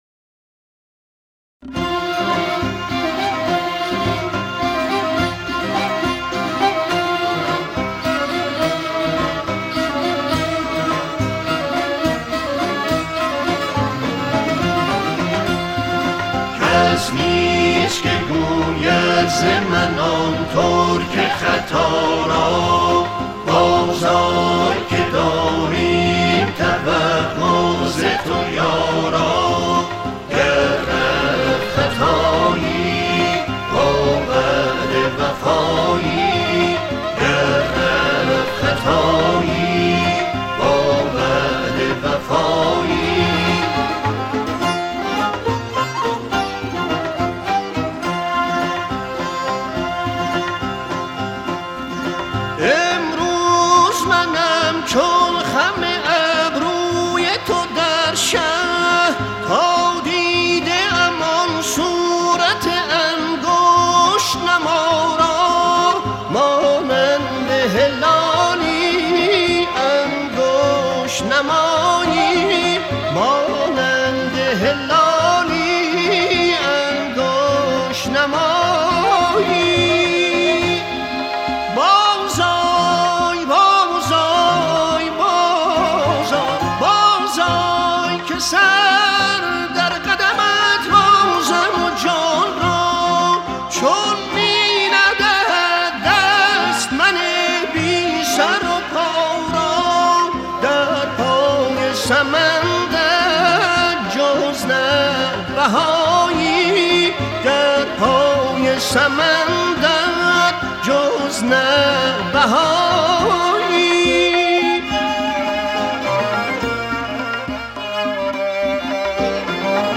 اهنگ سنتی